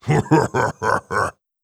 19. Ha ha ha ha ha.wav